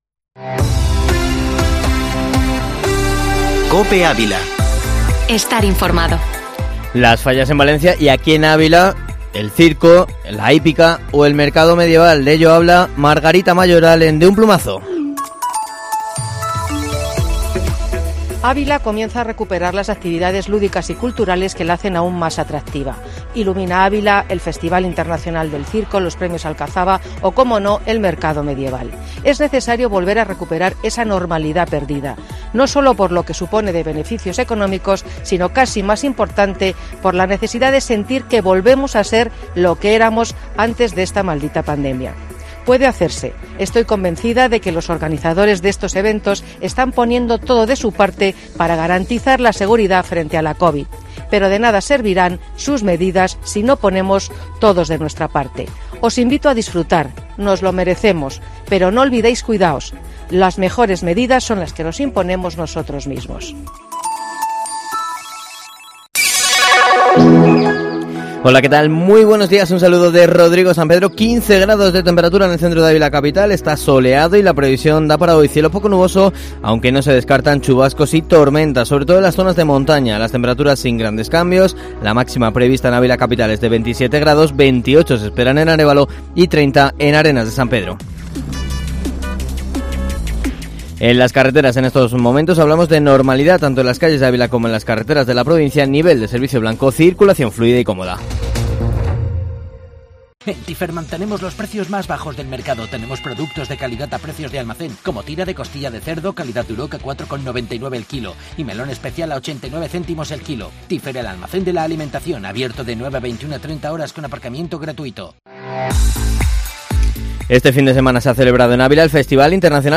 Informativo Matinal Herrera en COPE Ávila, información local y provincial